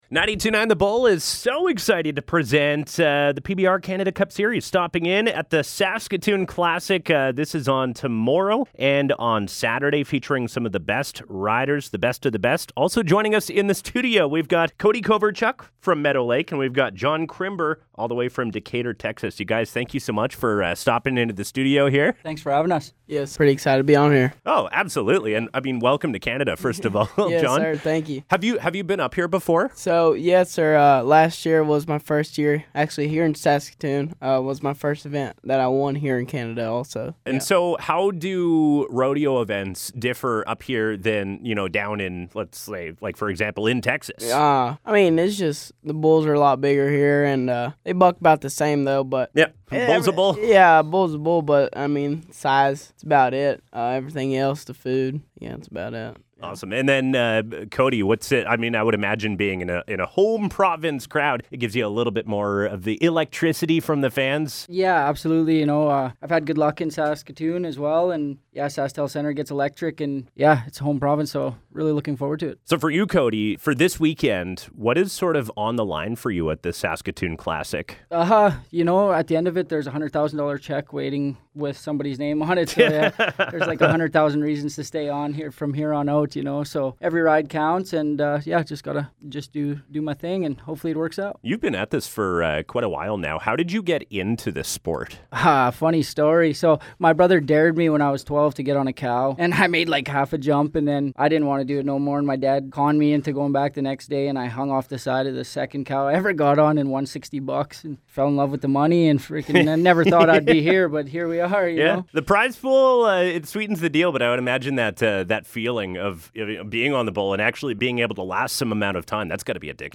Interview: PBR Saskatoon Classic